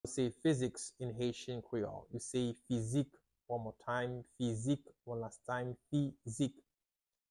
How to say "Physics" in Haitian Creole - "Fizik" pronunciation by a native Haitian tutor
“Fizik” Pronunciation in Haitian Creole by a native Haitian can be heard in the audio here or in the video below:
How-to-say-Physics-in-Haitian-Creole-Fizik-pronunciation-by-a-native-Haitian-tutor.mp3